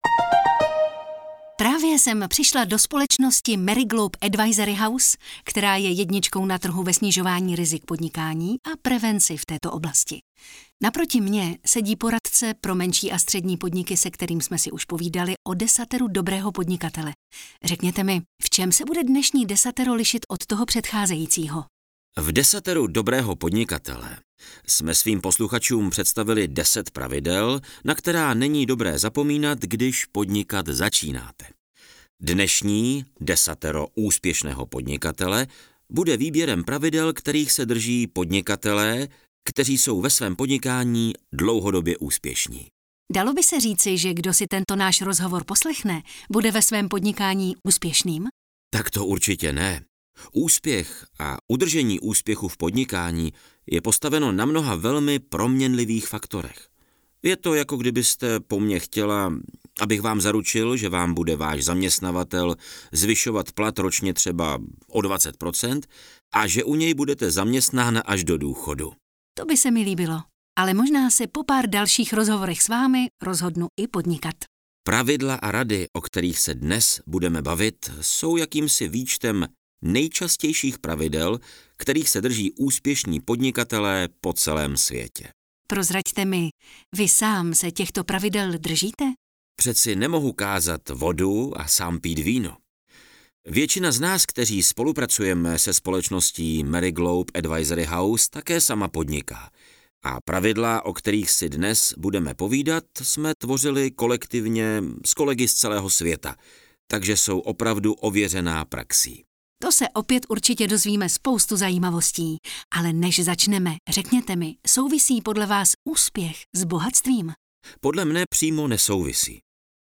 AudioKniha ke stažení, 1 x mp3, délka 51 min., velikost 117,0 MB, česky